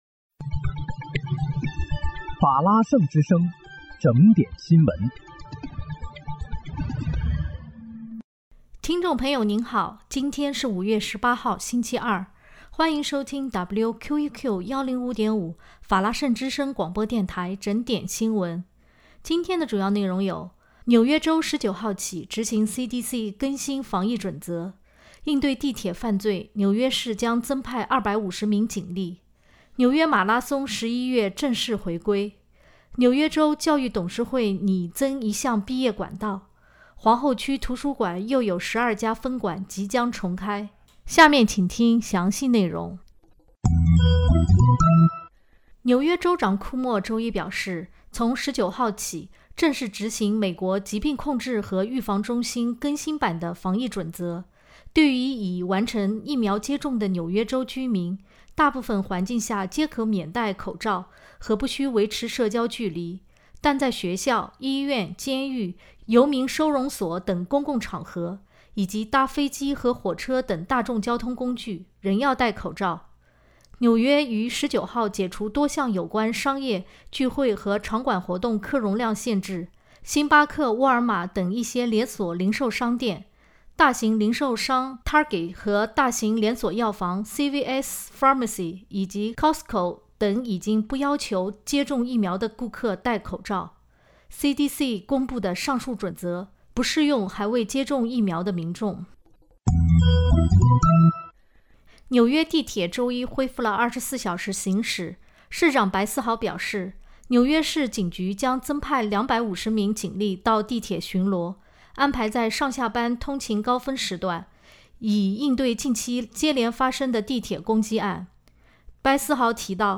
5月18日（星期二）纽约整点新闻